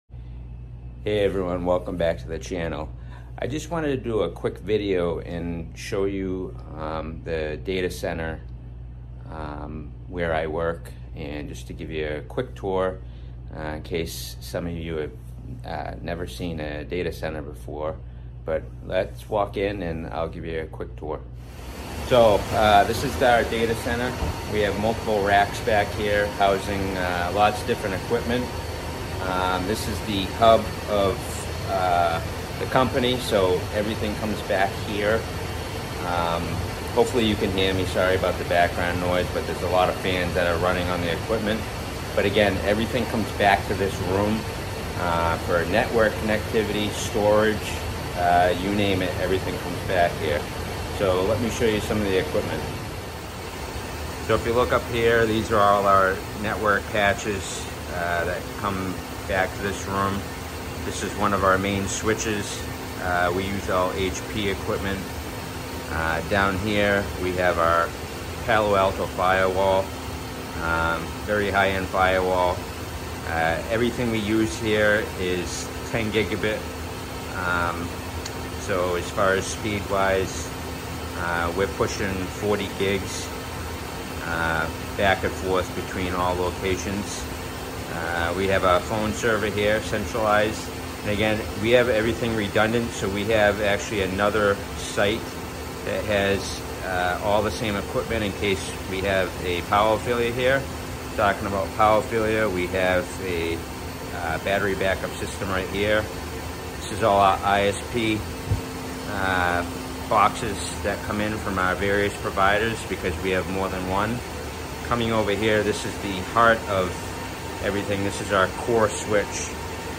Inside the Network Data Center sound effects free download